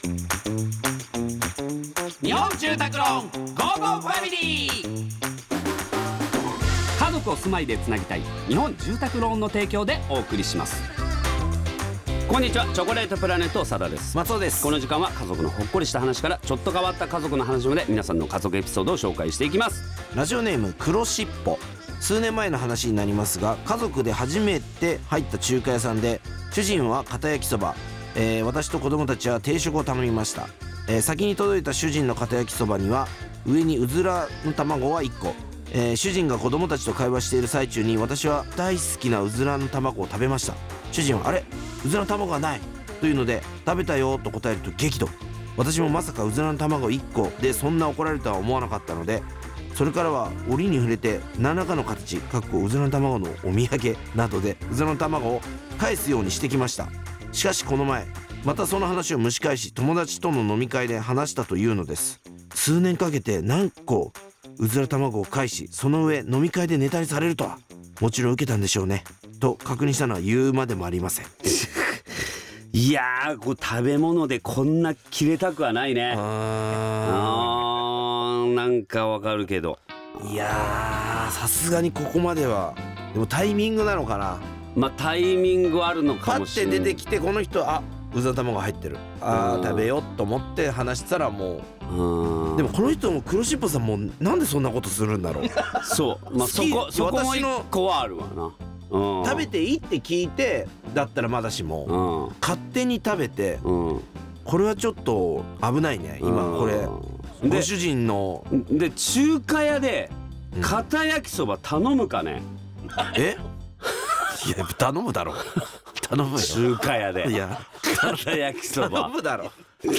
勝手に食べる人と、いつまでも根に持つ人、 どっちが悪いのかをふたりが論争！